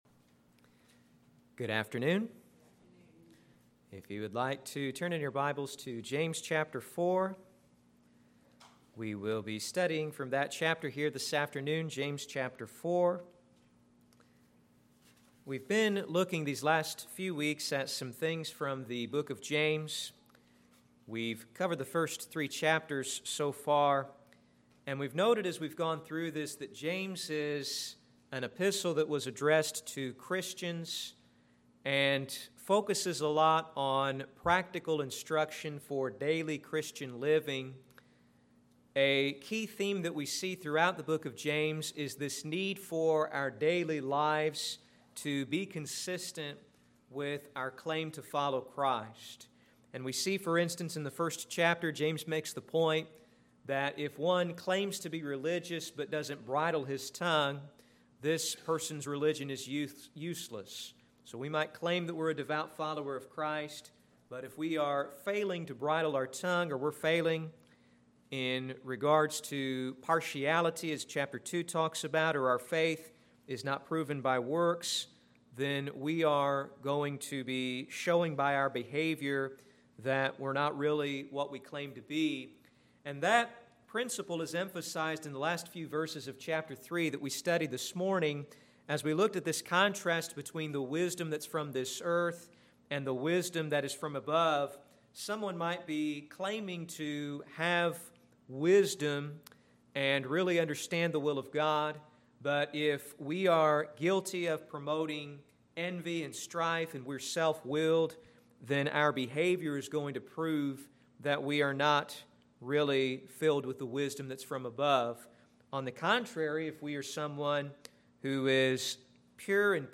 Sermons
Service: Sunday PM